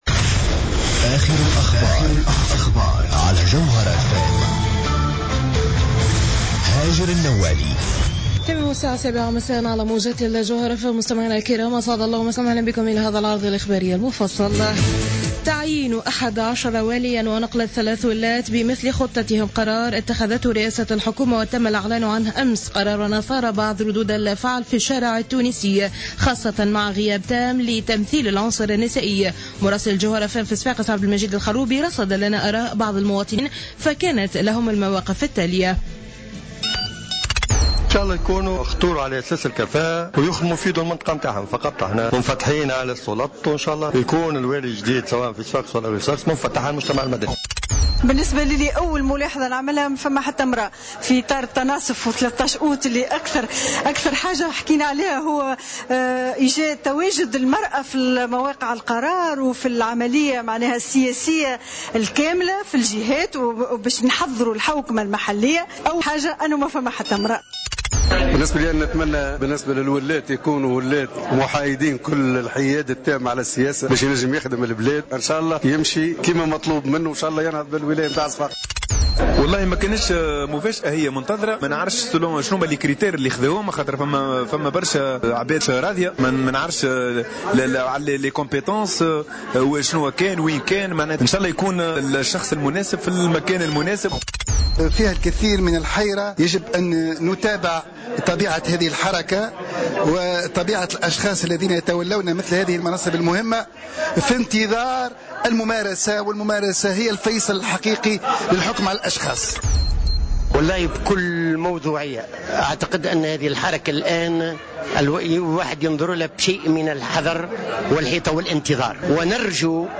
نشرة أخبار السابعة مساء ليوم الأحد 23 أوت 2015